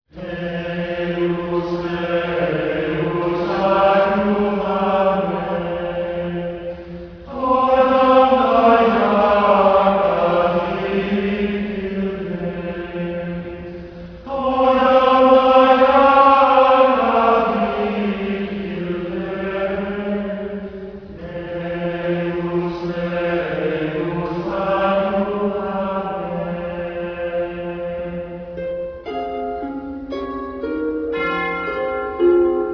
Irish Harp
Organ
Percussion
Violin
Uileann Pipes